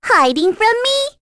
Luna-Vox_Skill1.wav